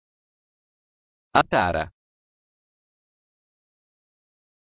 Attara ރަސްމީލެޓިނުން ކިޔާގޮތް. މީ އަރަބިން عطارة